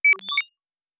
pgs/Assets/Audio/Sci-Fi Sounds/Interface/Data 01.wav at 7452e70b8c5ad2f7daae623e1a952eb18c9caab4